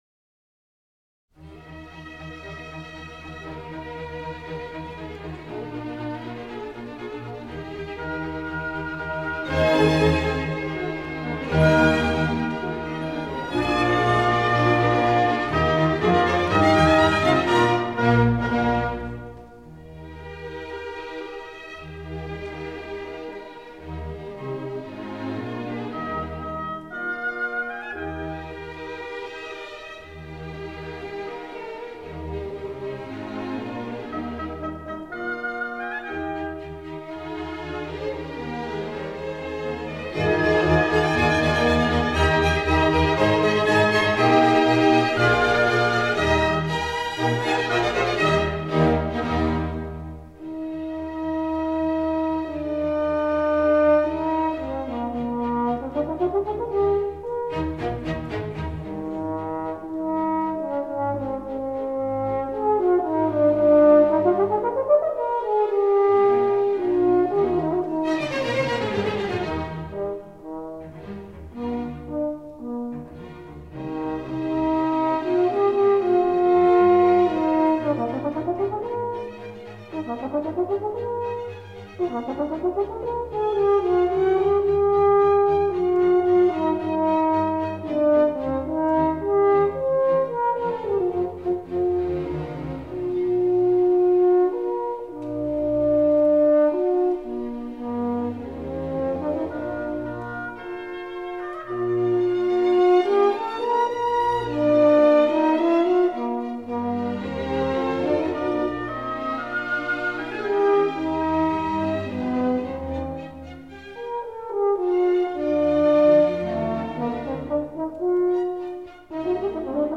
D04-Horn-Concerto-No.-2-in-E-flat-maj.mp3